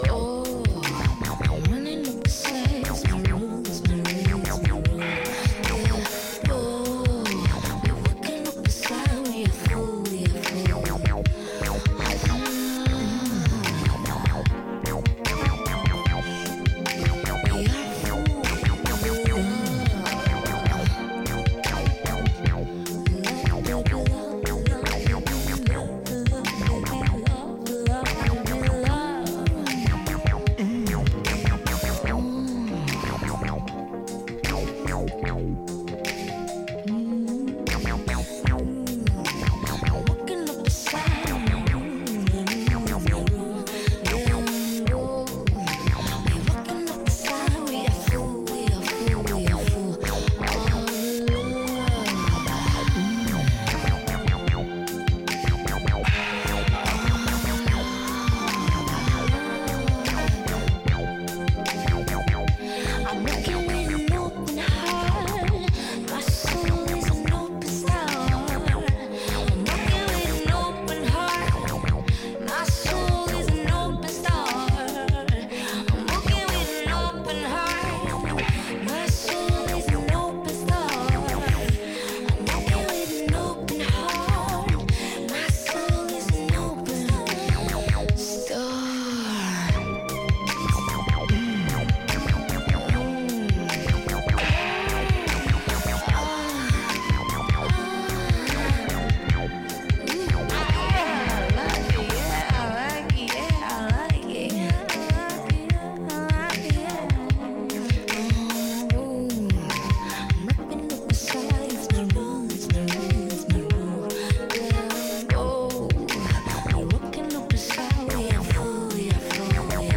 formed a live band
Disco Funk Boogie